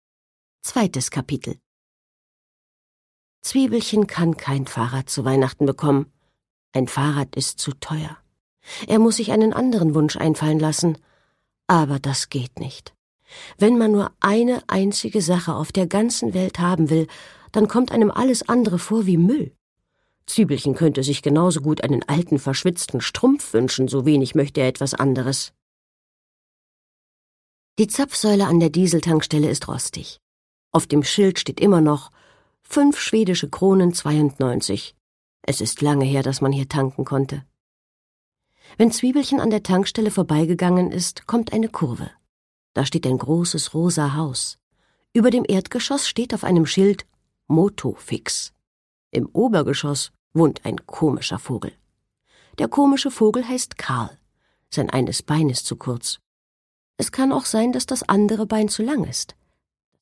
Produkttyp: Hörbuch-Download
Fassung: Ungekürzte Lesung